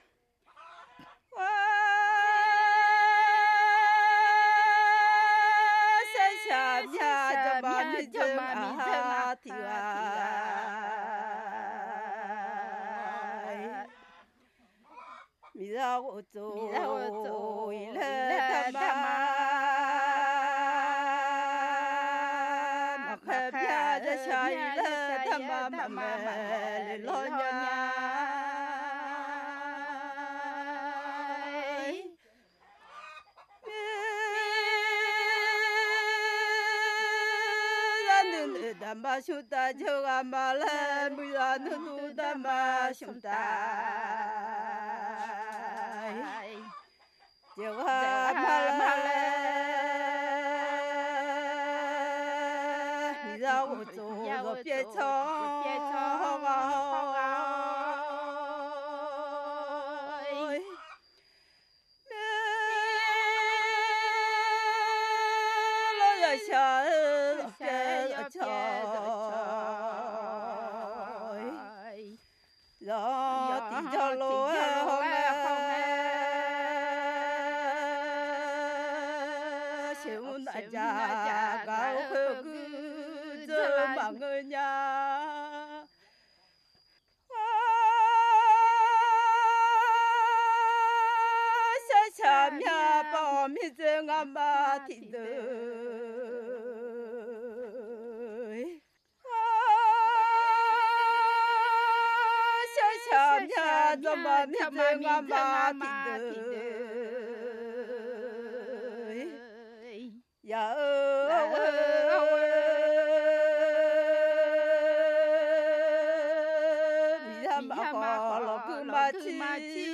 Akha music
song (two female singers) "I thought that once I was married life would be good with no work but it's harder than living with my parents" 1.9MB
Track 04 Akha song.mp3